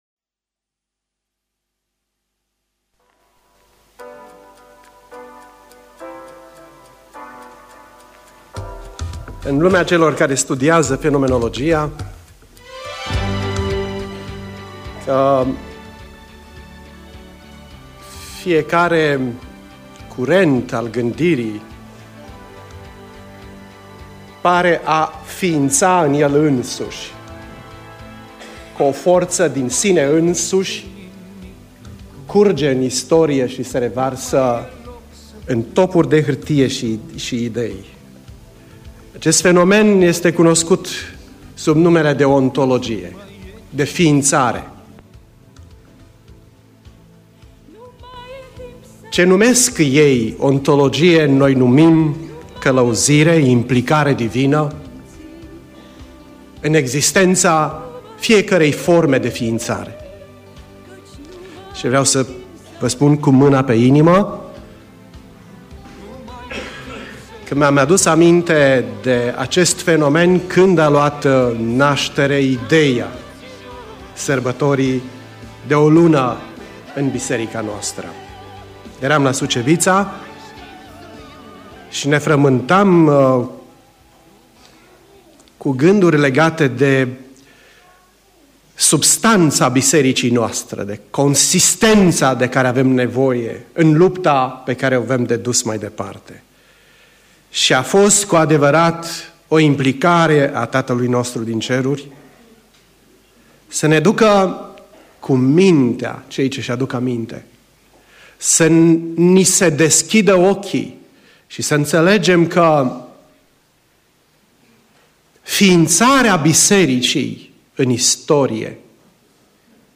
Predica Aplicatie - Dragostea